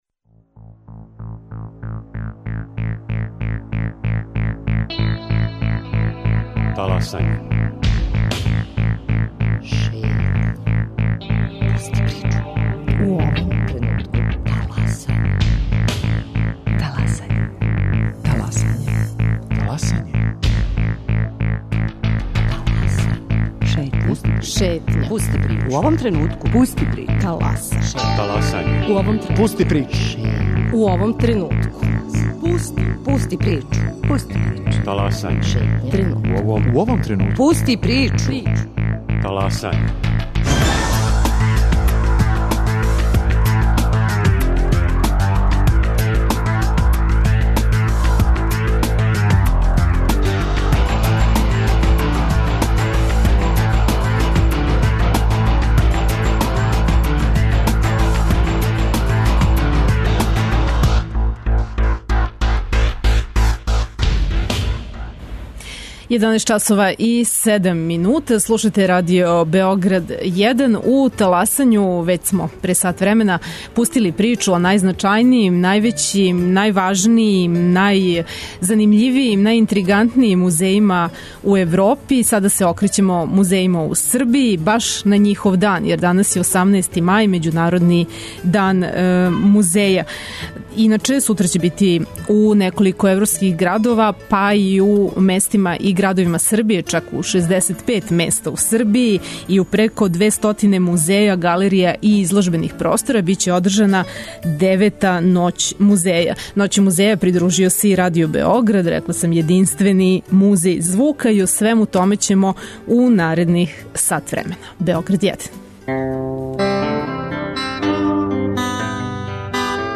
Наши саговорници биће организатори манифестације, представници музејских и изложбених простора.